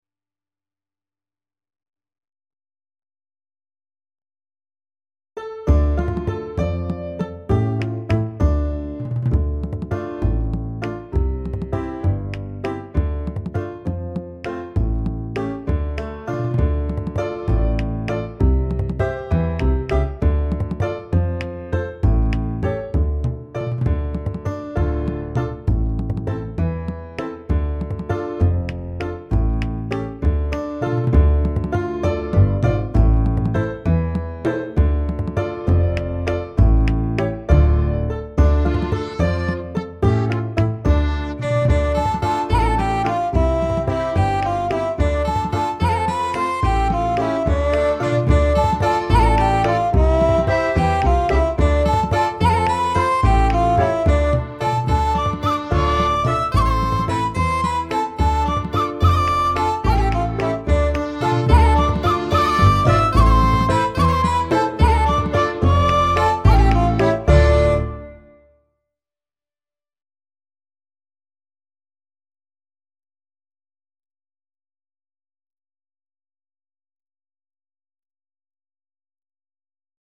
19 The Basket of Turf (Backing Track)